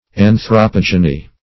Anthropogeny \An`thro*pog"e*ny\, n. [Gr.